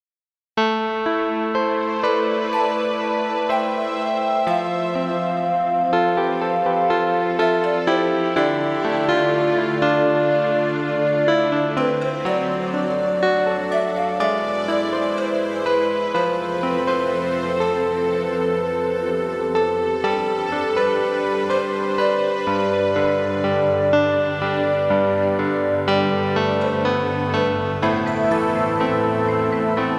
Home > Lullabies